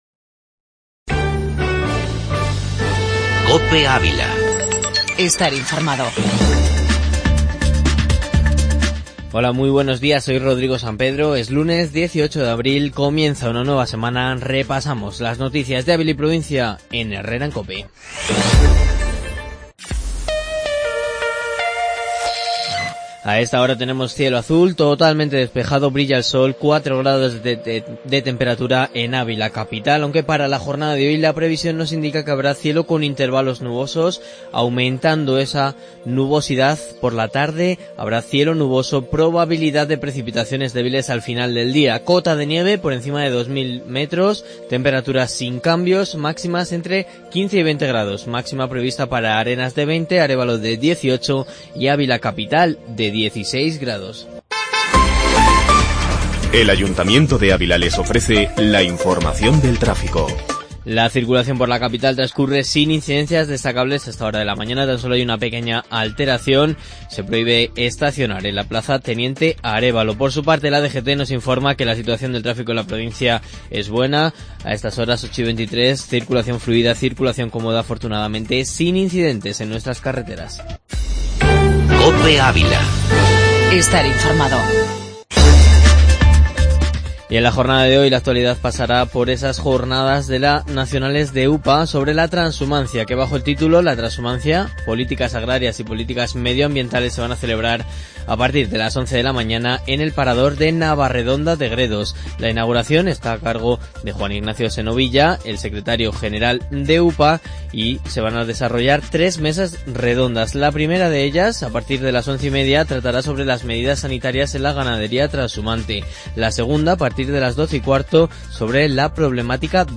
AUDIO: Informativo La Mañana en Ávila. Información local y provincial en 'Herrera en Cope'.